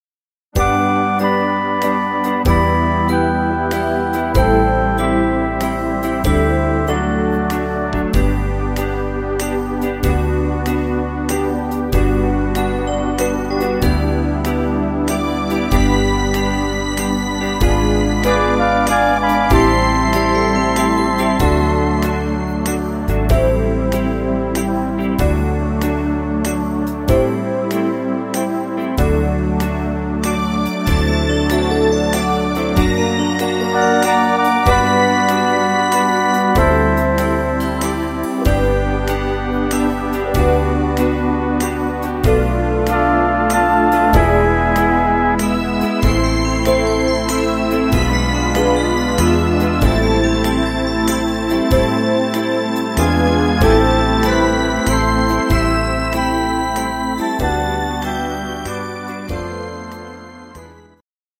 instr. Strings